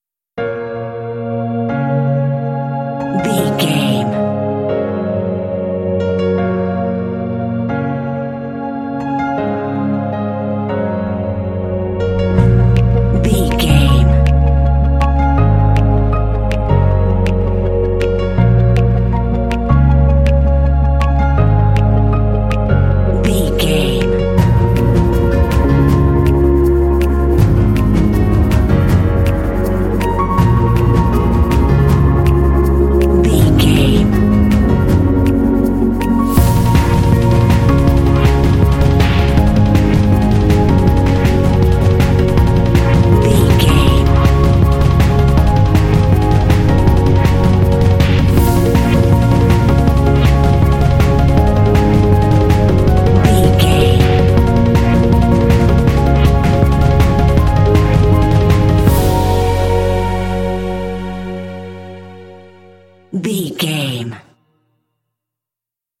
Uplifting
Epic / Action
In-crescendo
Aeolian/Minor
B♭
Fast
driving
energetic
piano
percussion
drums
strings
synthesiser
synth-pop